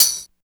99 HAT 2.wav